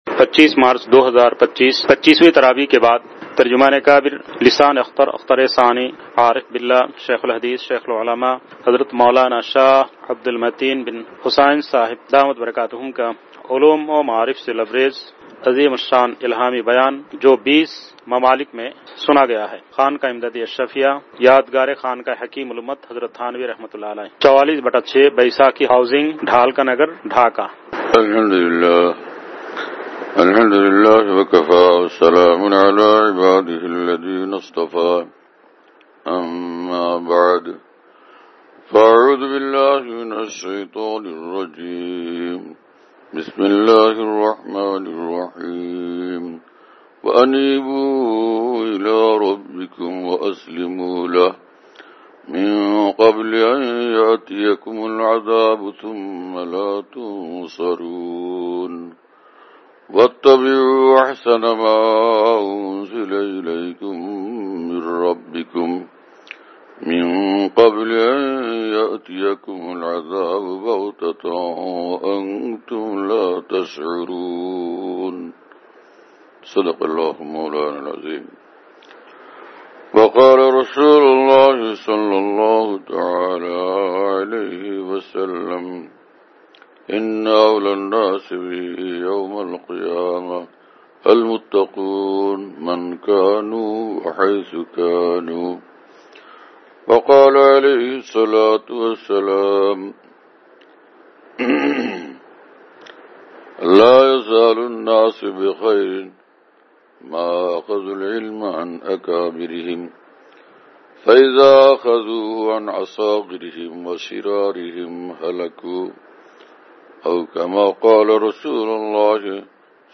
۲۵ مارچ ۲۰۲۵ءبعد تراویح : ۲۵ شب رمضان المبارک !